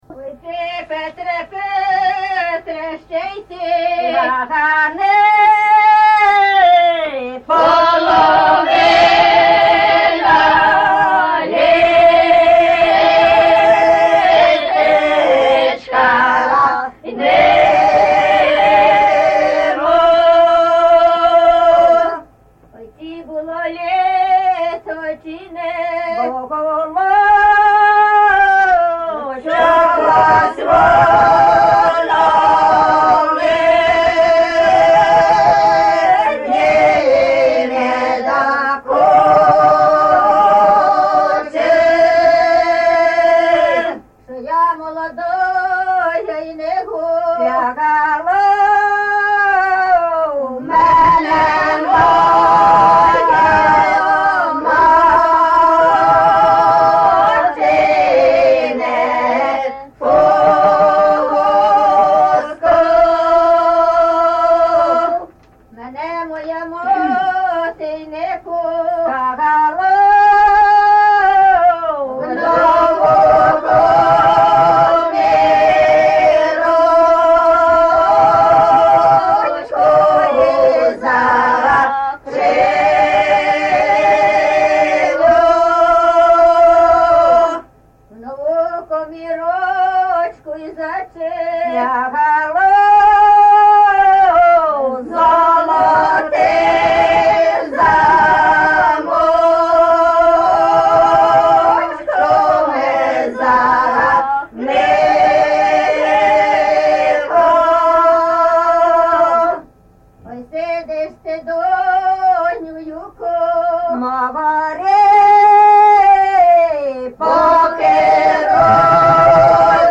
ЖанрПетрівчані
Місце записус. Лука, Лохвицький (Миргородський) район, Полтавська обл., Україна, Полтавщина